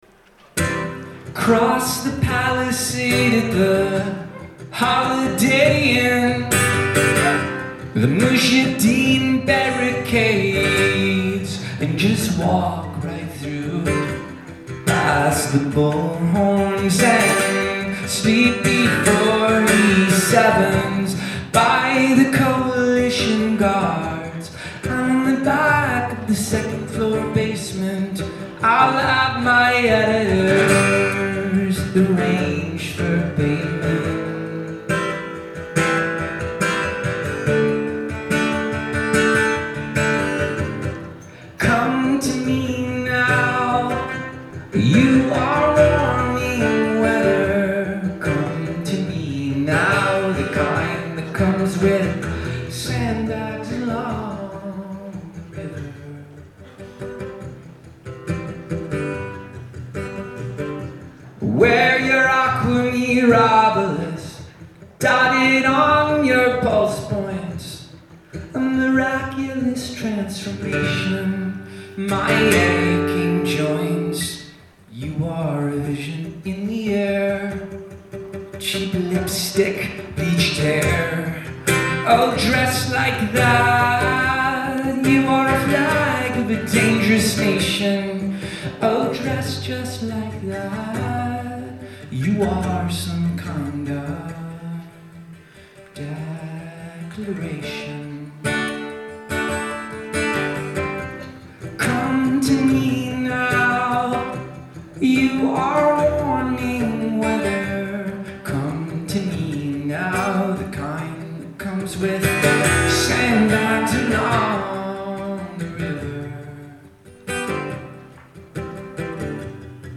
Live at the Somerville Theatre